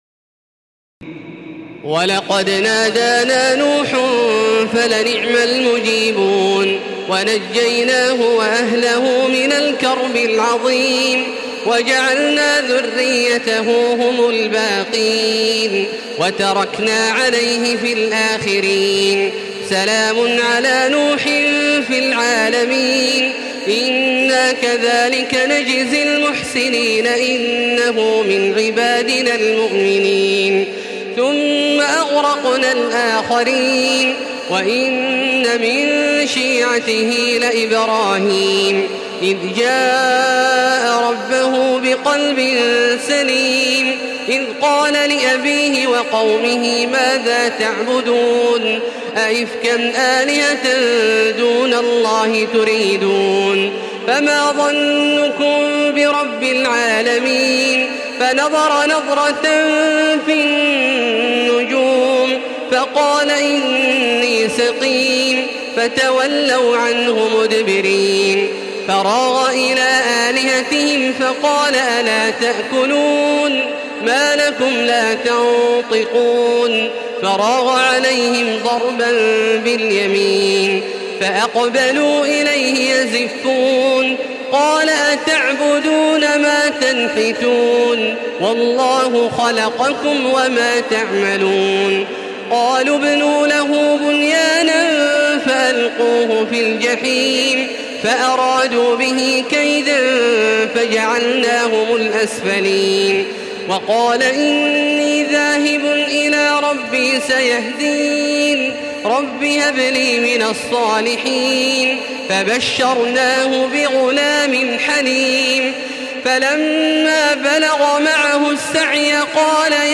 تراويح ليلة 23 رمضان 1435هـ من سور الصافات (75-182) وص و الزمر (1-21) Taraweeh 23 st night Ramadan 1435H from Surah As-Saaffaat and Saad and Az-Zumar > تراويح الحرم المكي عام 1435 🕋 > التراويح - تلاوات الحرمين